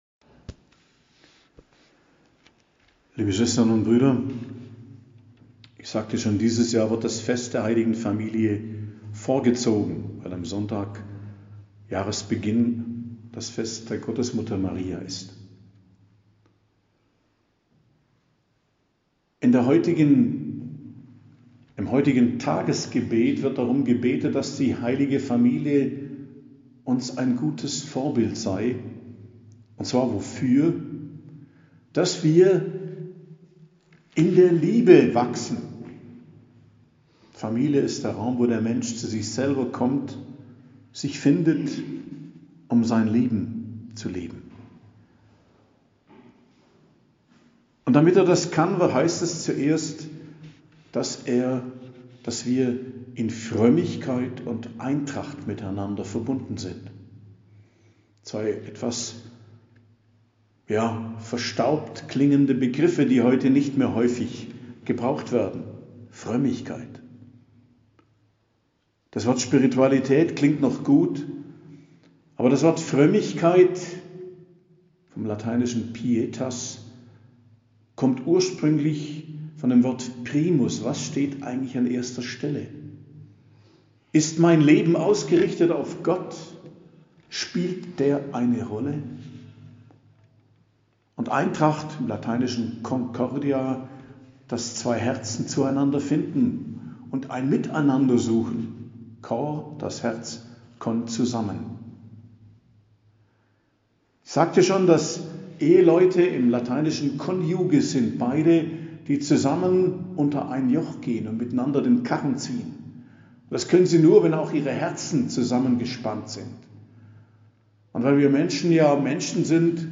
Predigt am Fest der Heiligen Familie, 30.12.2022